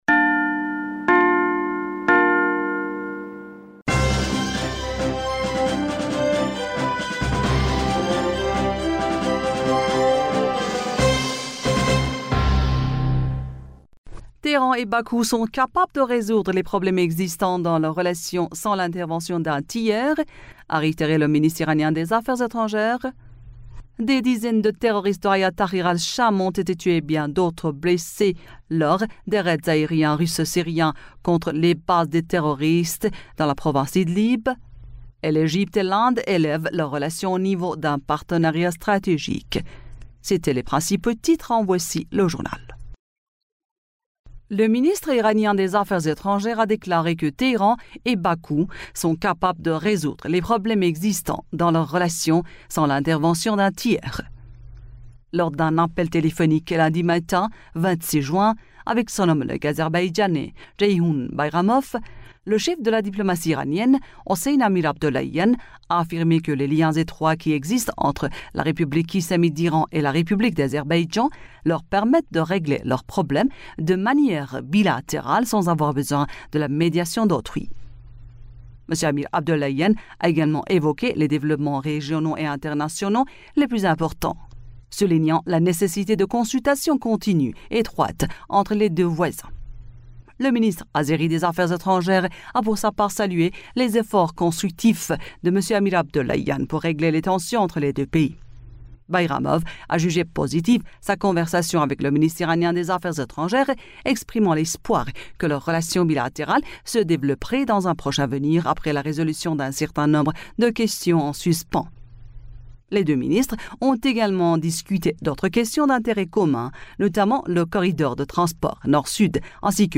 Bulletin d'information du 26 Juin 2023